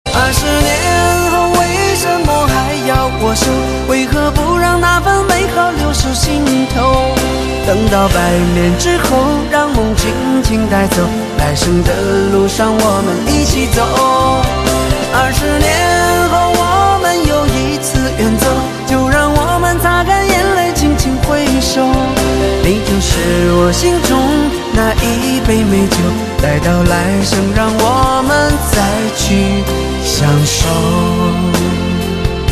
M4R铃声, MP3铃声, 华语歌曲 30 首发日期：2018-05-15 09:26 星期二